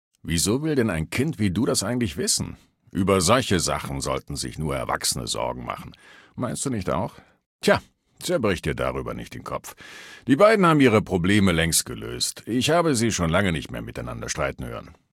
Sep. 2025 Maleadult02 mq04 mq04residentrockwells 00095b45.ogg (Datei)
136 KB Charakter: Bill Foster Kategorie:Fallout 3: Audiodialoge 1